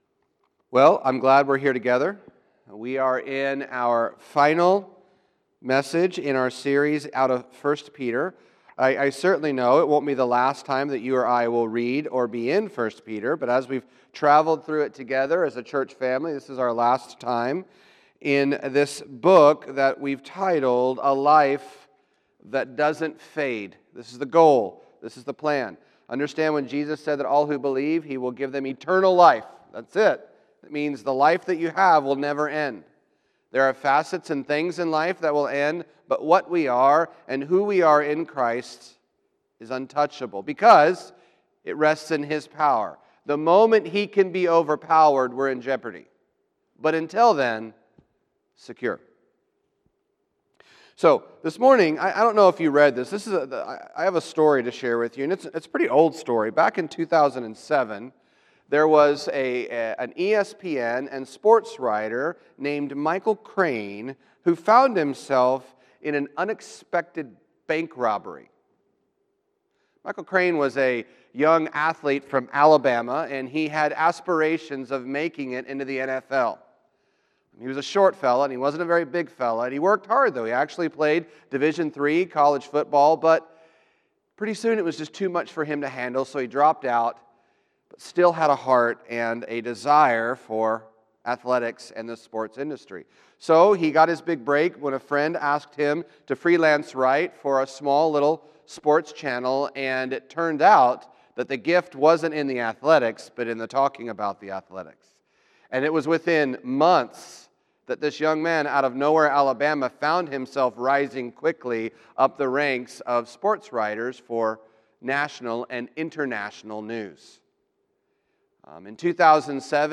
SERIES: 1 PETER: A Life that doesn’t fade Sermon title – A Bump in the Road